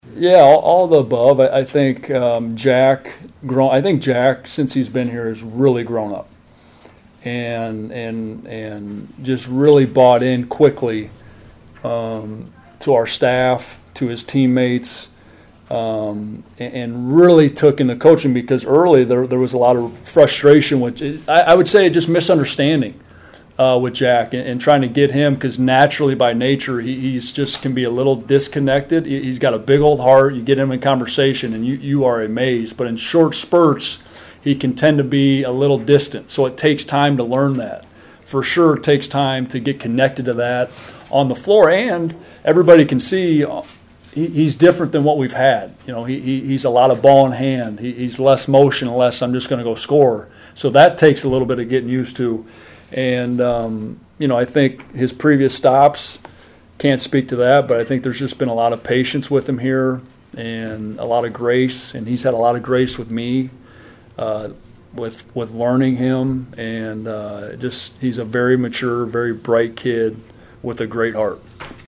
POSTGAME PRESS CONFERENCE EXCERPTS